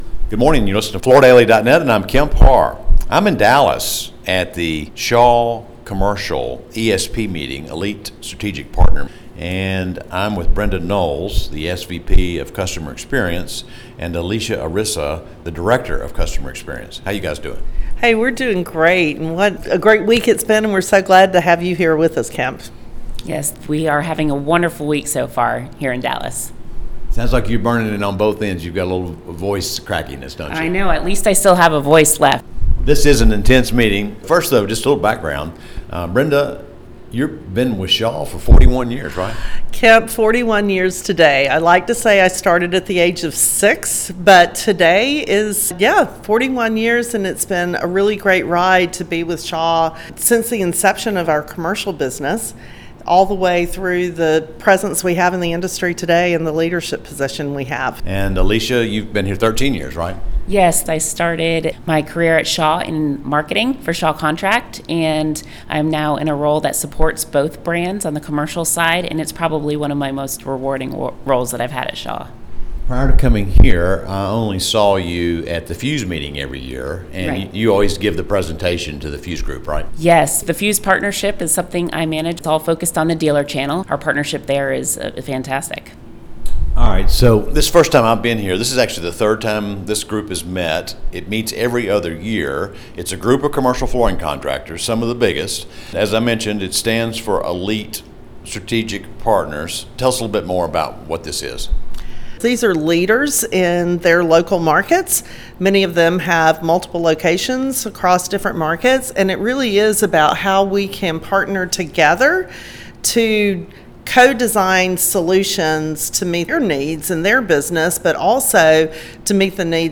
Listen to the interview to hear more about this meeting with a select group of commercial flooring contractors and Shaw’s outlook on market conditions in the near term.